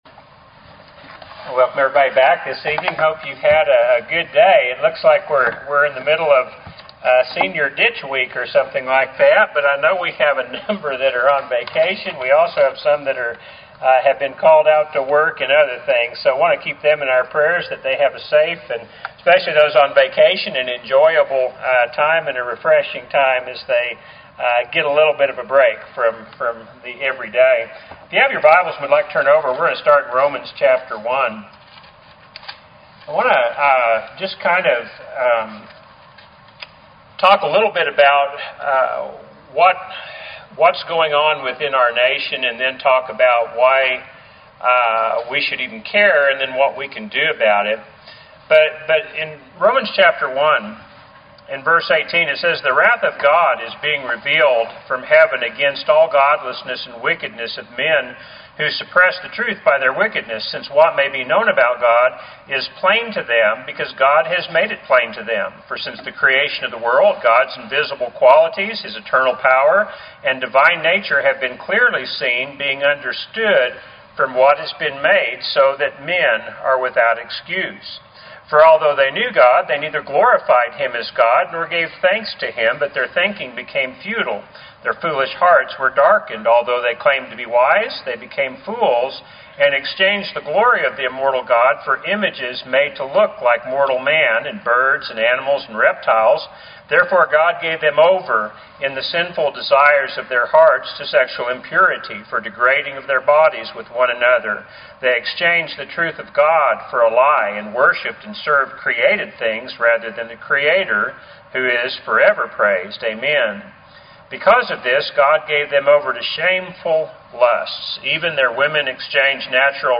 Weekly sermons